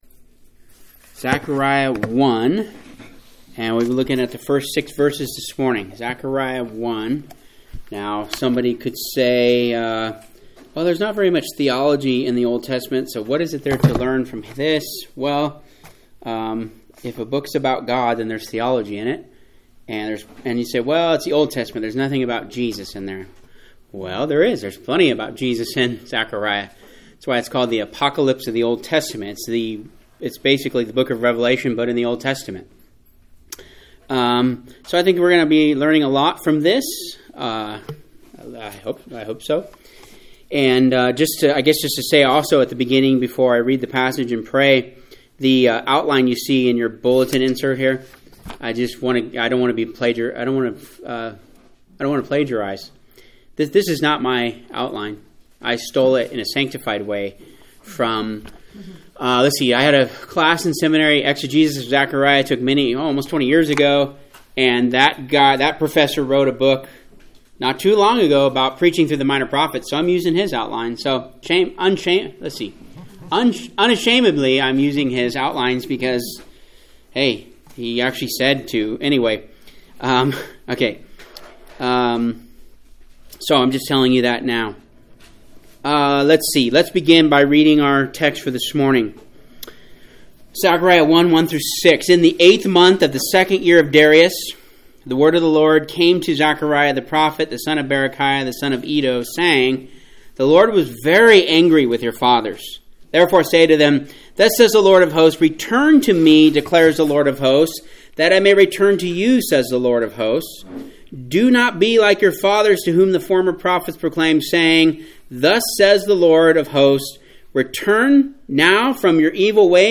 Technical difficulty around 5:52, maybe 5 to 20 seconds cut out.